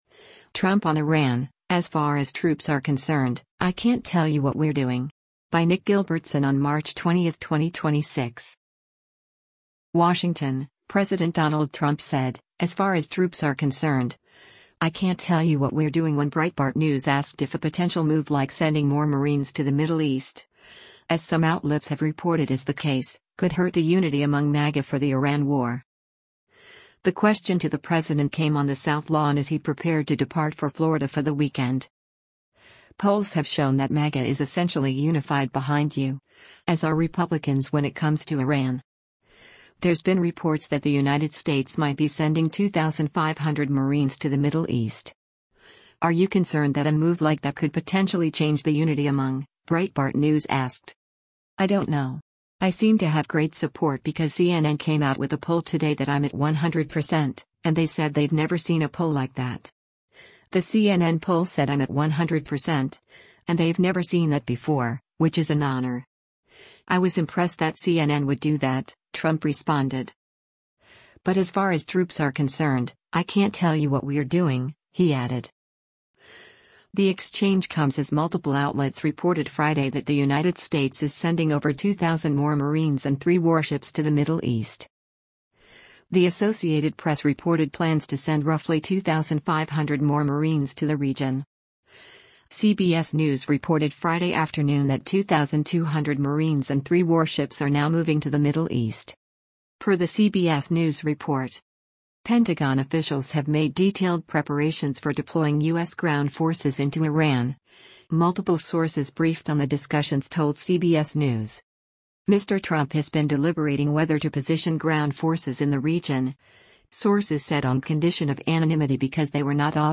The question to the president came on the South Lawn as he prepared to depart for Florida for the weekend.